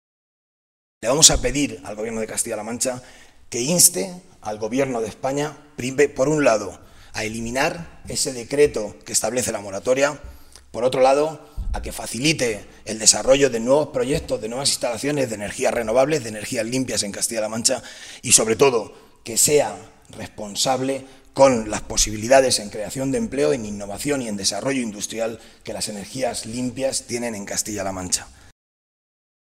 Modesto Belinchón, diputado regional del PSOE de Castilla-La Mancha
Cortes de audio de la rueda de prensa